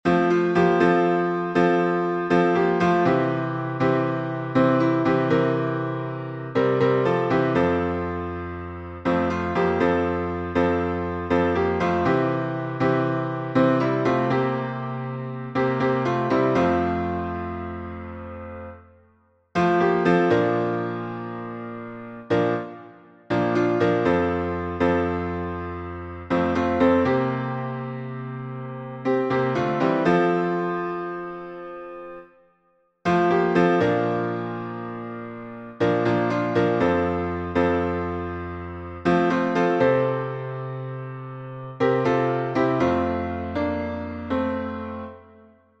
My Redeemer — F major.